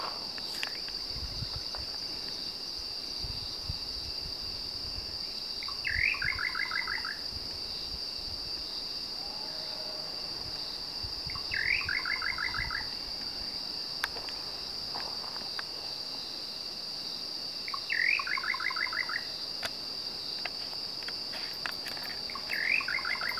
Bacurau-chintã (Setopagis parvula)
Nome em Inglês: Little Nightjar
Fase da vida: Adulto
Localidade ou área protegida: Paso de la Patria
Condição: Selvagem
Certeza: Gravado Vocal